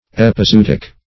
Epizootic \Ep`i*zo*["o]t"ic\, Epizooty \Ep`i*zo"["o]*ty\, n. [F.